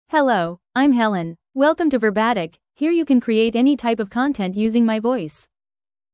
FemaleEnglish (United States)
HelenFemale English AI voice
Helen is a female AI voice for English (United States).
Voice sample
Listen to Helen's female English voice.
Helen delivers clear pronunciation with authentic United States English intonation, making your content sound professionally produced.